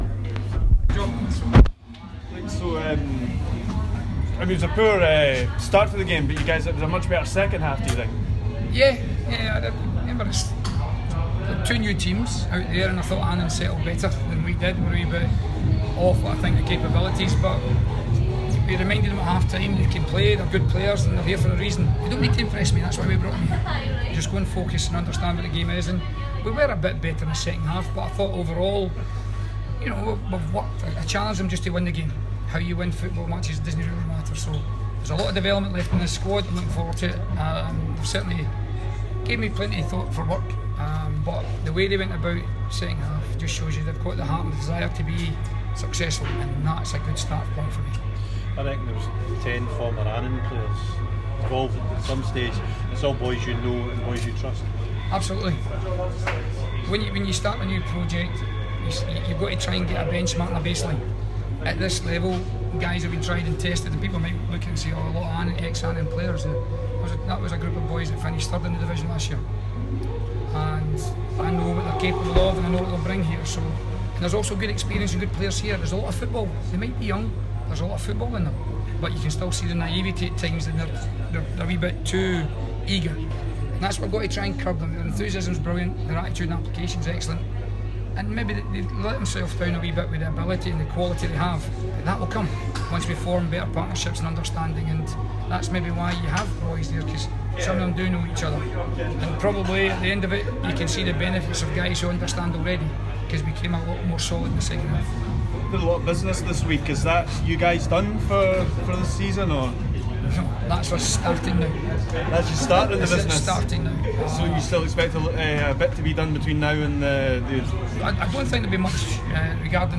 press conference after the Betfred Cup match.